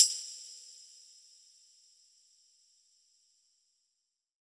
HP133PERC1-R.wav